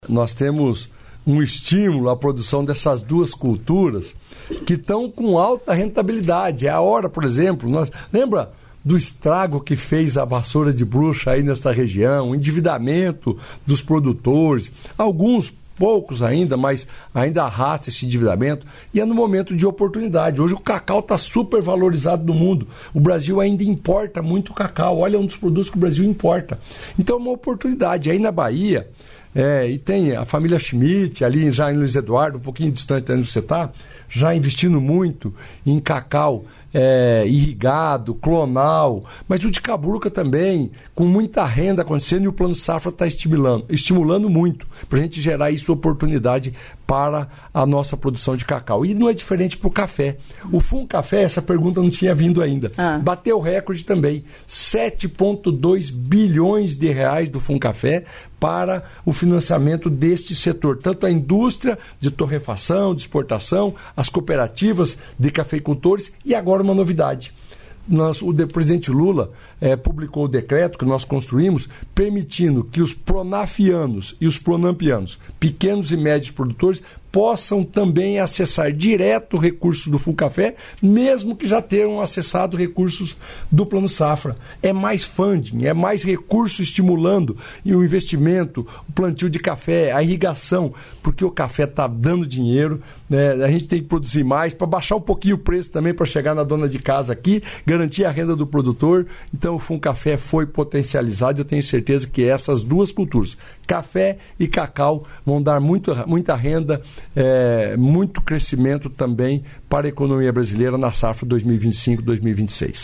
Trecho da entrevista concedida pela ministra das Mulheres, Cida Gonçalves, nesta quinta-feira (22), para emissoras de rádio de todo o país, direto dos estúdios da EBC, em Brasília.